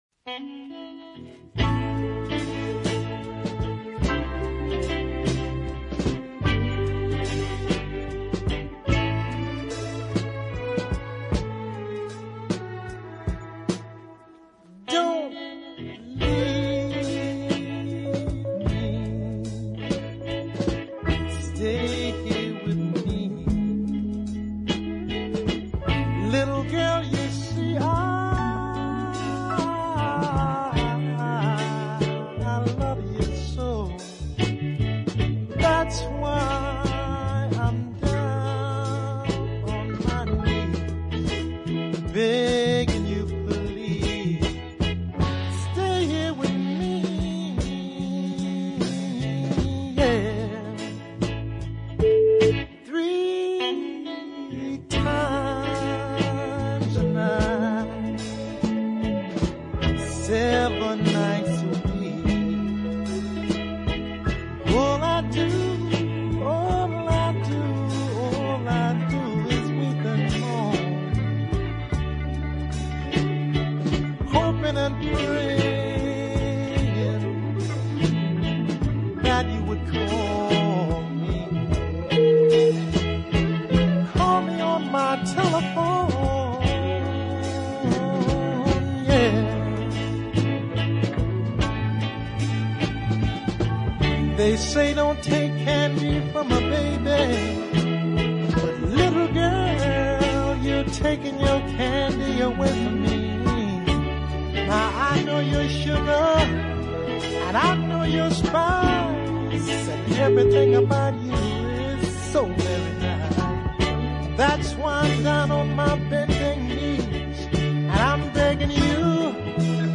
delicate tenor vocal